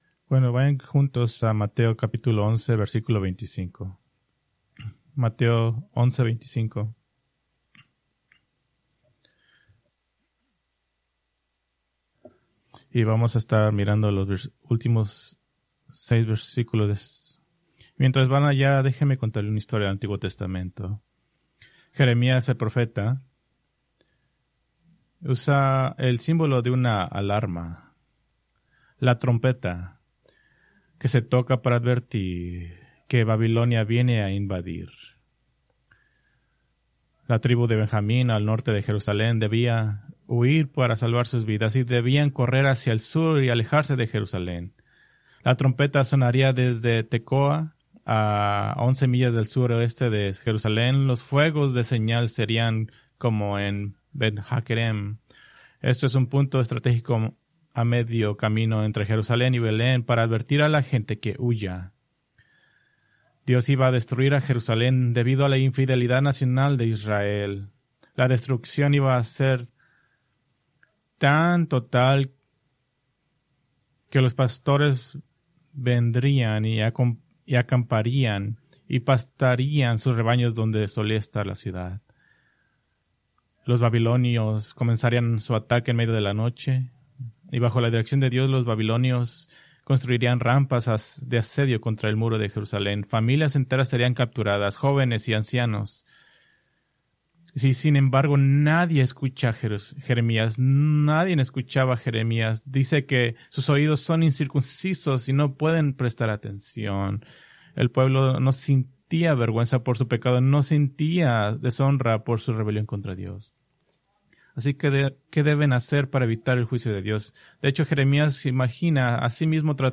Preached October 19, 2025 from Mateo 11:25-30